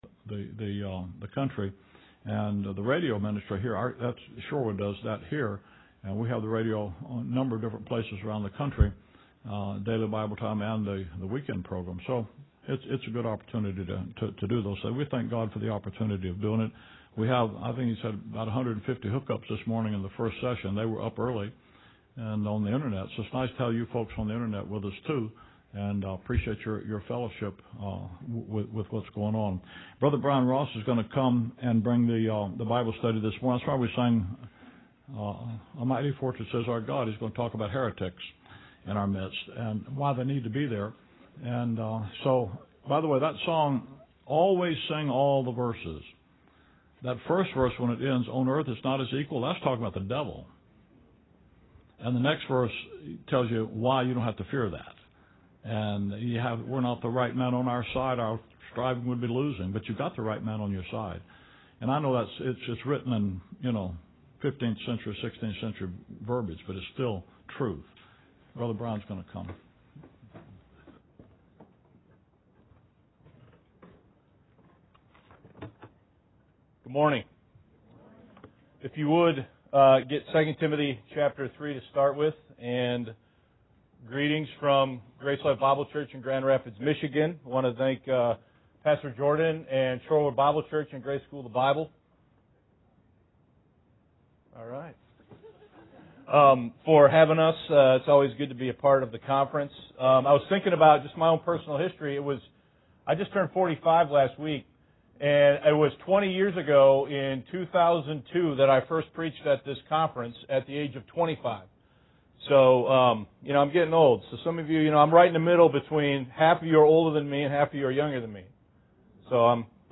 Why Must There Be Heresies Among Us? 1 Cor. 11:19 (GSB Conference Message)